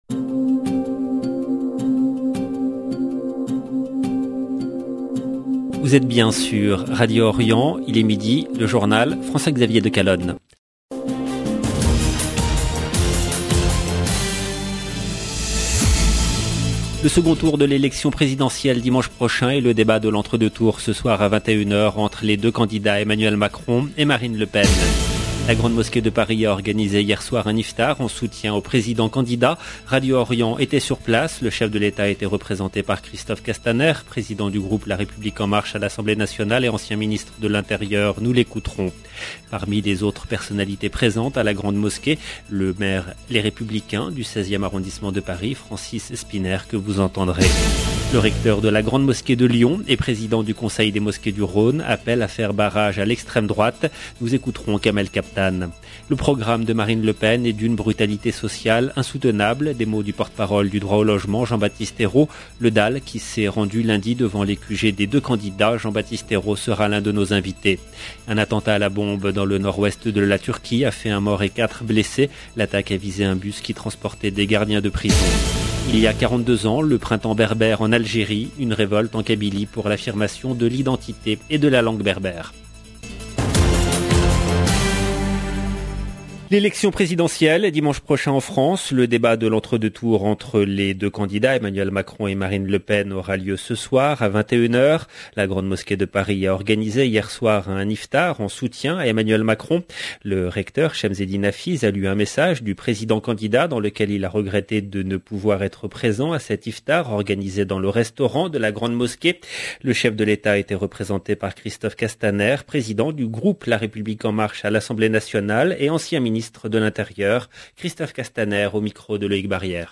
LE JOURNAL DE MIDI EN LANGUE FRANCAISE DU 20/04/22
Présidentielle Islam 20 avril 2022 - 17 min LE JOURNAL DE MIDI EN LANGUE FRANCAISE DU 20/04/22 LB JOURNAL EN LANGUE FRANÇAISE Le second tour de l’élection présidentielle dimanche prochain et le débat de l‘entre deux tours ce soir à 21h entre les deux candidats Emmanuel Macron et Marine Le Pen. La Grande Mosquée de Paris a organisé hier soir un iftar en soutien au président candidat. Radio Orient était sur place.